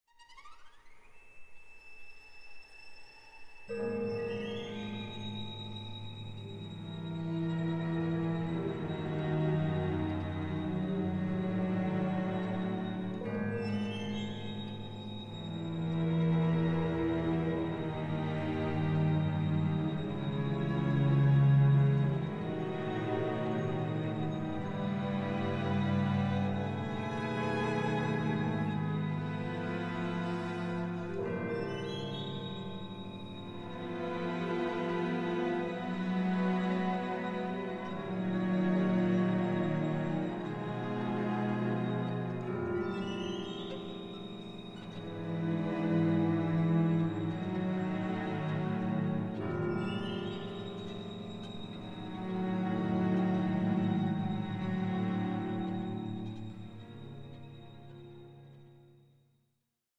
A COSMIC, ADVENTUROUS PAIRING OF VIOLIN CONCERTOS